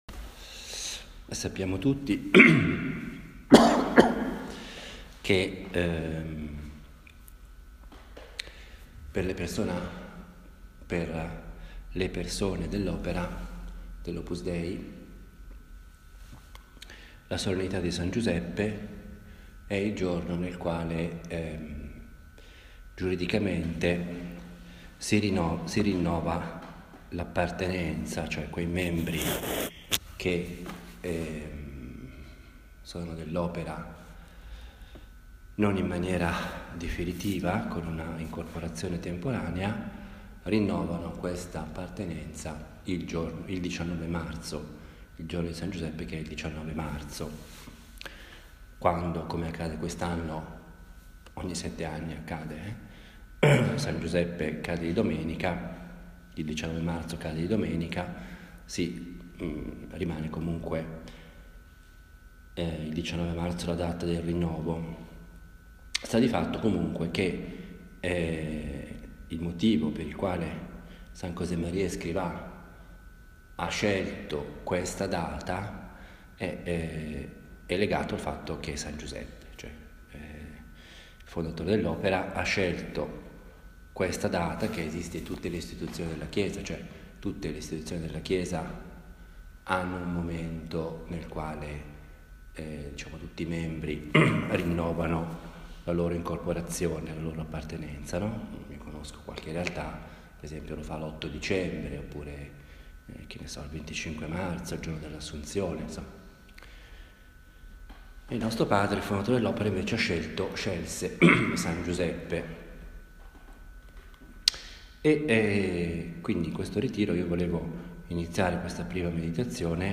Prima parte Seconda parte Una “meditazione” è un genere omiletico diverso dalla predica, dal discorso, o dall’allocuzione.
Ha il carattere piano, proprio di una conversazione familiare e io la intendo come il mio dialogo personale – fatto ad alta voce – con Dio, la Madonna, ecc. In genere do un titolo alle meditazione e cerco di fare molto riferimento alla scrittura, in particolare al vangelo. Le meditazioni che si trovano sul blog sono semplici registrazioni – senza nessuna pretesa particolare – di quelle che faccio abitualmente.